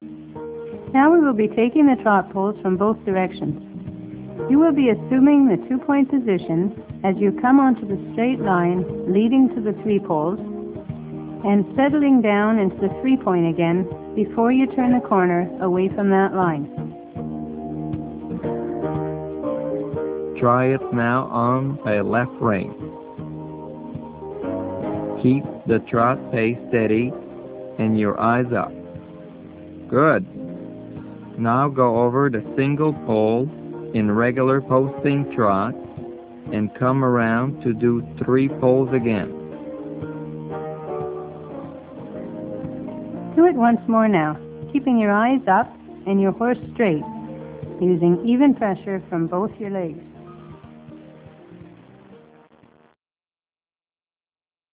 Jumping lessons given by one of the Grand Prix circuit's most accomplished and competitive riders, Mario Deslauriers. This level provides jumping enthusiasts with structured schooling sessions and many exercises and ideas to improve your jumping skills.